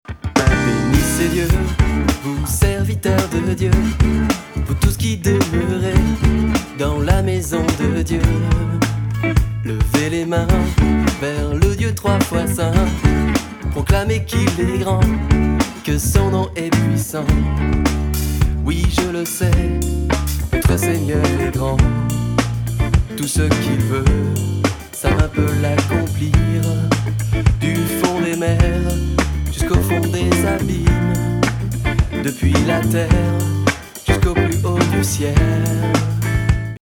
Louange (405)
guitare
lead vocal et choeurs
ainsi que des compositions originales jazzy
Pris dans une telle ambiance de louange et de contemplation
Format :MP3 256Kbps Stéréo